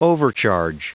Prononciation du mot overcharge en anglais (fichier audio)
Prononciation du mot : overcharge